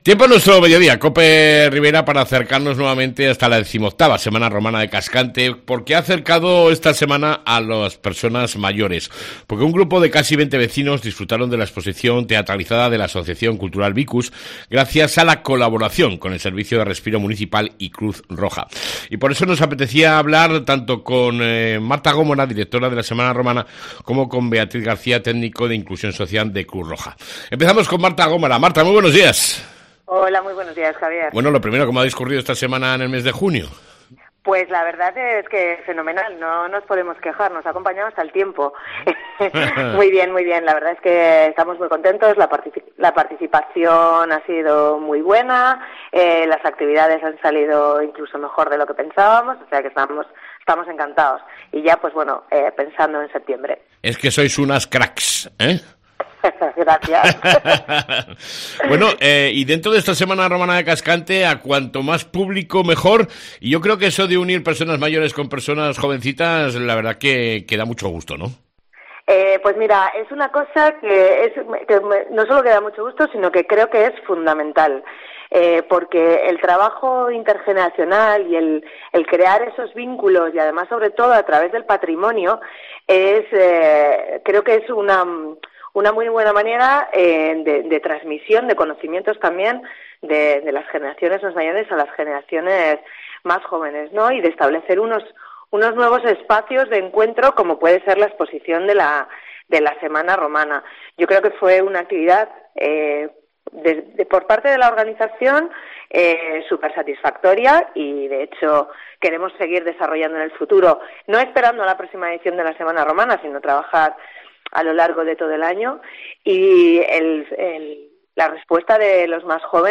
ENTREVISTA CON LA SEMANA ROMANA Y CRUZ ROJA EN CASCANTE